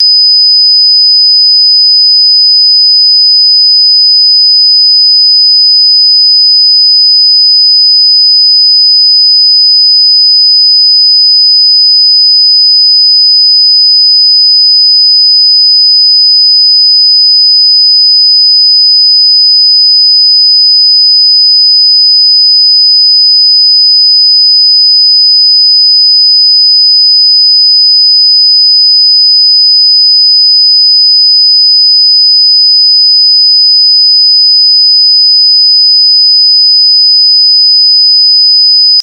周波数5000Hz 着信音
聞く者にとって耳障りな音と感じられる特徴的な音です。この高周波音は、まるでモスキートの鳴き声のように耳につき、人々の感覚を刺激します。驚くことに、60歳以上の方でも聞こえるとされています。